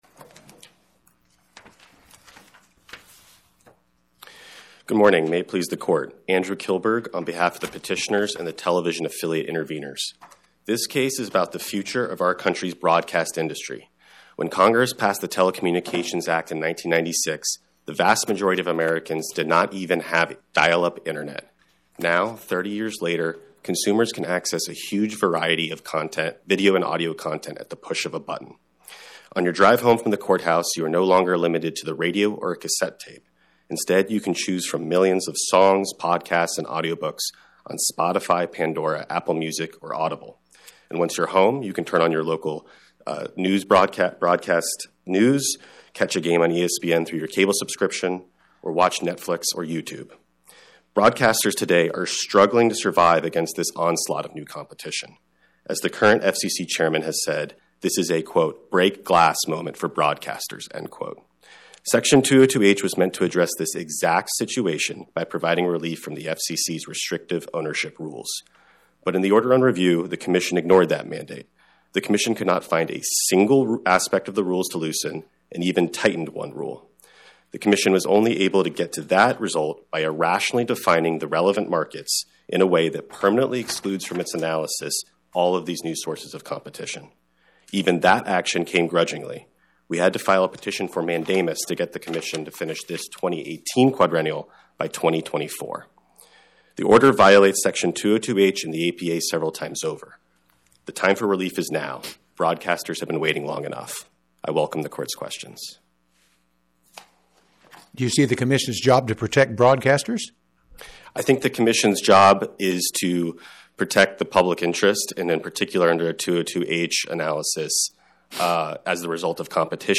FCC counsel responded that the Court should defer to the FCC’s policy judgement that the rules were still necessary to preserve the localism provided by broadcast stations. Many questions were asked of both sides by the three-judge panel. A recording of the argument is available on the Court’s website here .